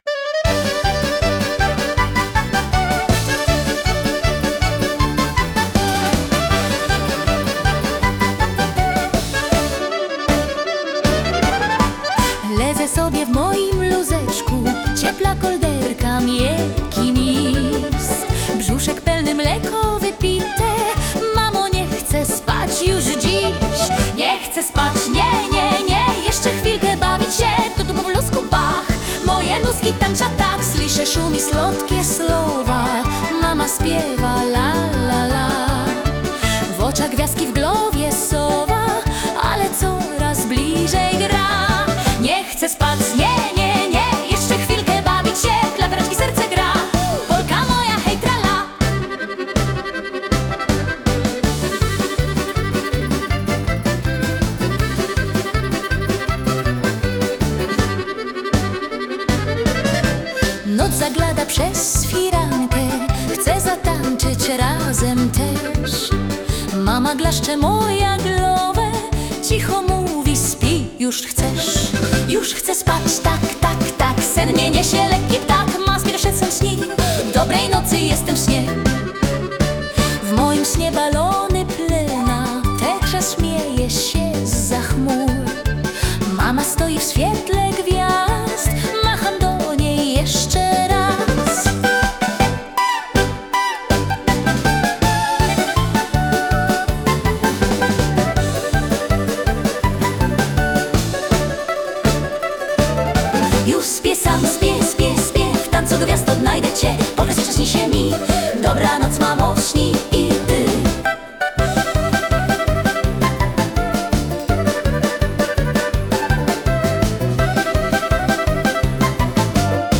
Es ist satt, geborgen und neugierig, doch statt einzuschlafen, möchte es noch spielen und die Welt entdecken. Diese Unruhe verbindet sich rhythmisch mit dem lebhaften Polka-Takt, der die kindliche Energie widerspiegelt.
Allmählich verwandelt sich das Toben in Müdigkeit, und der Polka-Rhythmus wird langsamer, bis das Kind in einen friedlichen, bunten Traum gleitet. Das Lied ist ein humorvoller, zugleich warmer Moment zwischen Spiel und Geborgenheit – ein musikalisches Wiegenlied im Polka-Gewand.